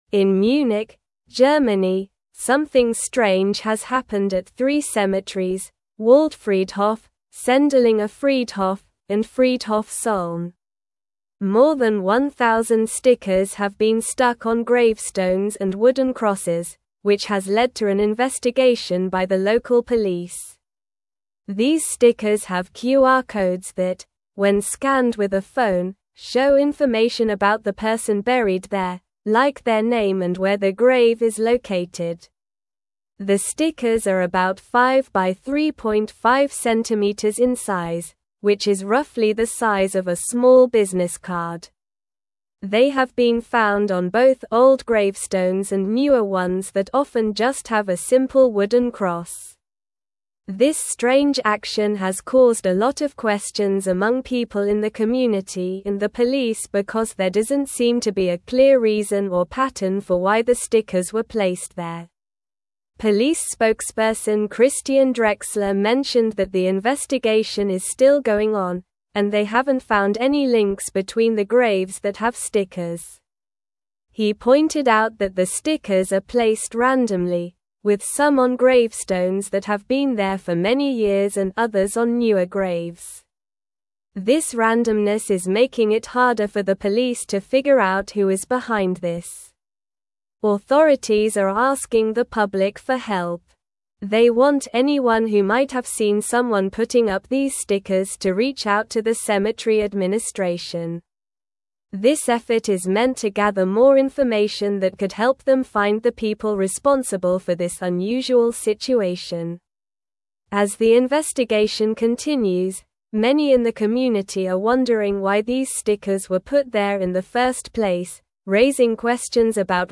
Slow
English-Newsroom-Upper-Intermediate-SLOW-Reading-QR-Code-Stickers-Appear-on-Gravestones-in-Munich.mp3